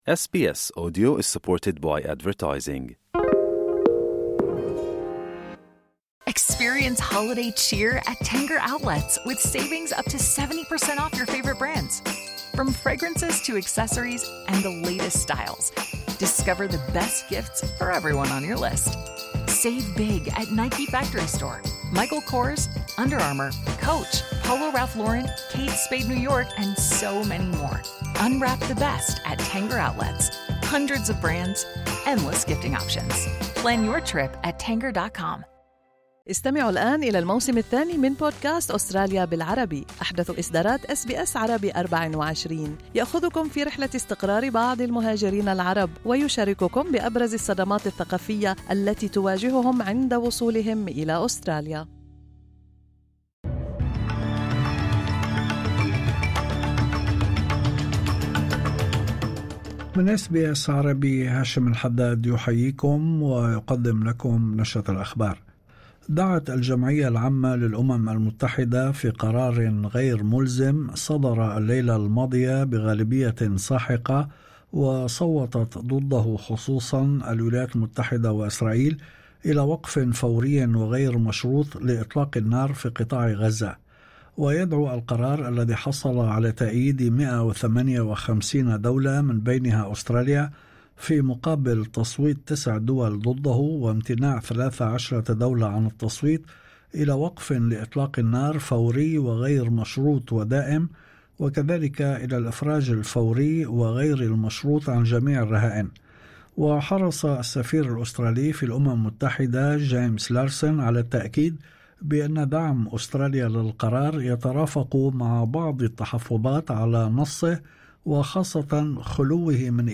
نشرة أخبار الظهيرة 12/12/2024